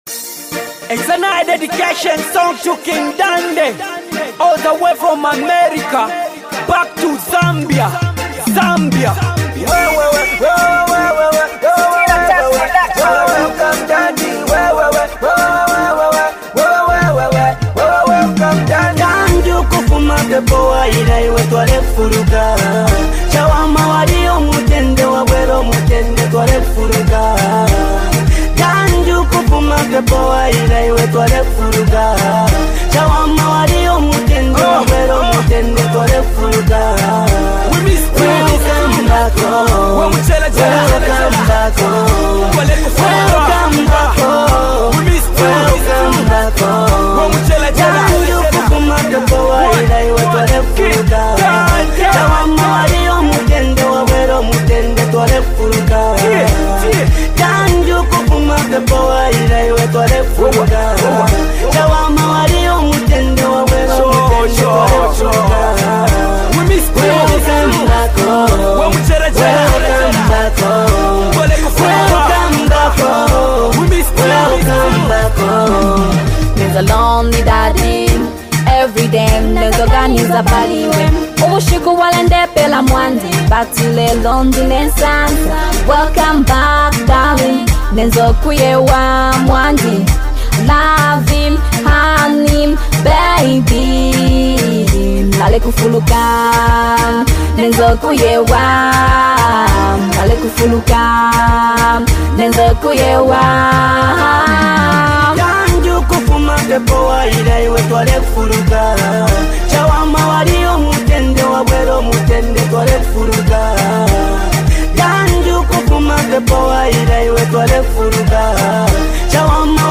collaborative anthem